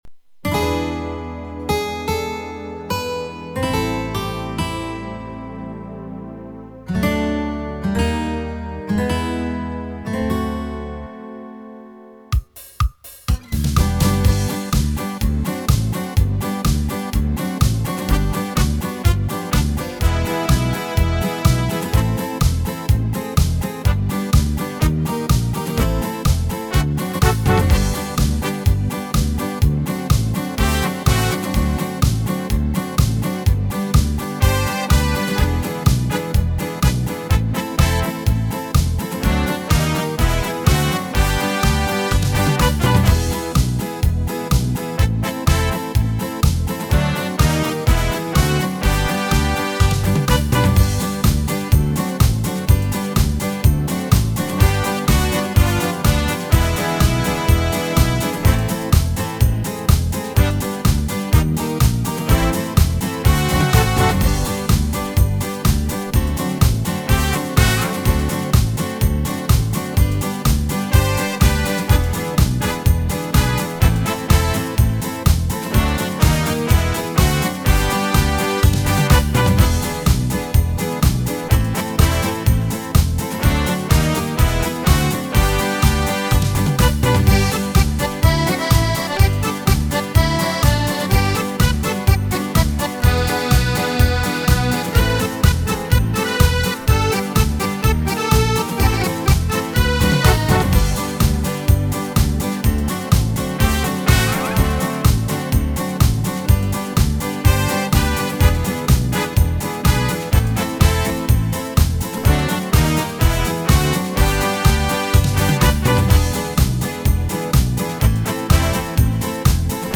Слушать или скачать минус